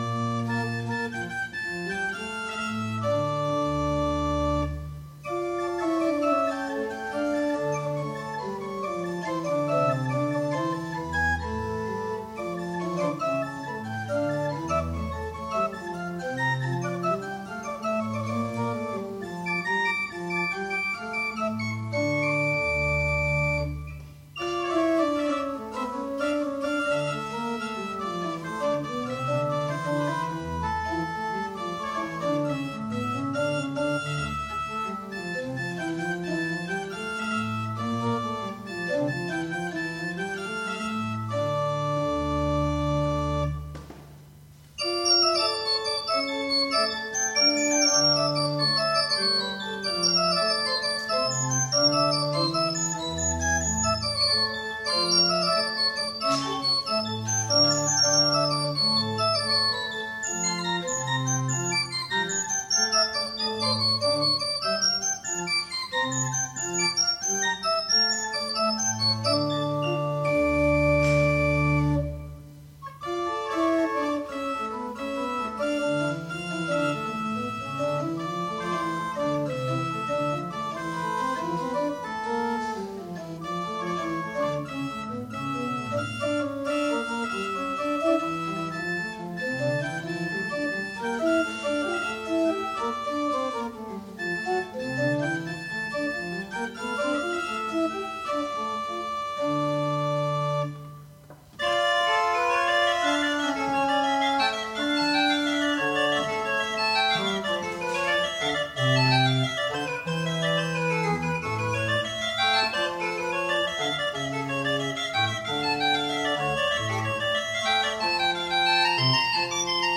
CONCERT DE NOËL 2021
Brass Band Val de Loire
Concert de Noël 2021 Brass Band Val de Loire Église Saint Victor d'Artenay Les amis de l'Orgue dimanche 12 décembre 2021